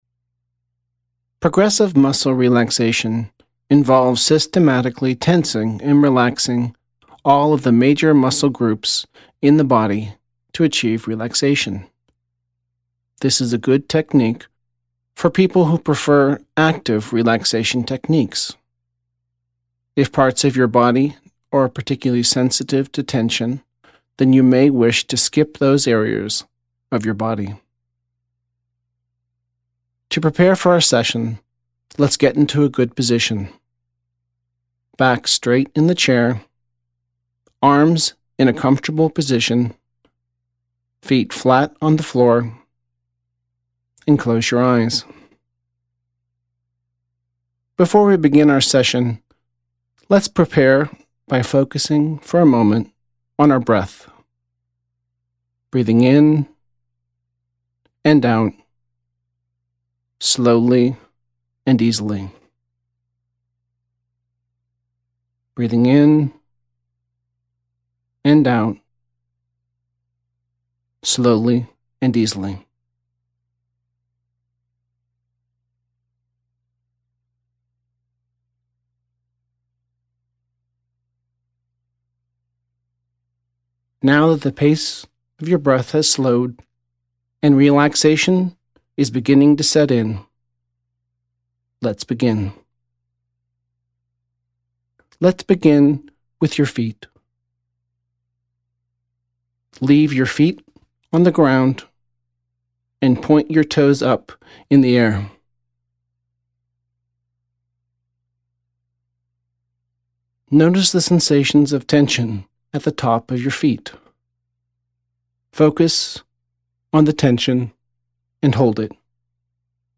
Progressive Muscle Relaxation Audio